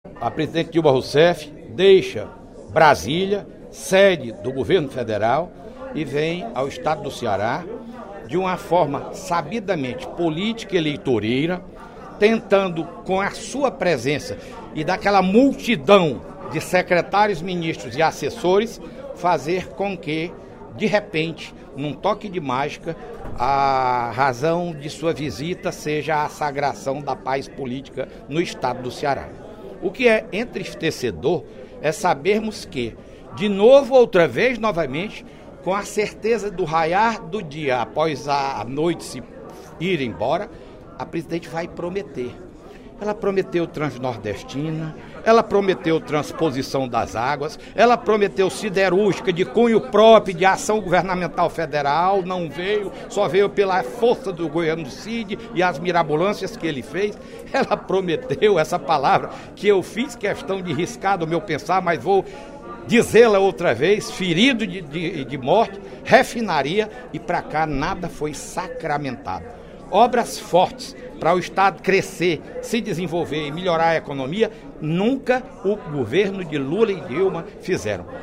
No primeiro expediente da sessão plenária desta terça-feira (18/03), o deputado Fernando Hugo (SDD) criticou a vinda da presidente Dilma Rousseff ao Ceará.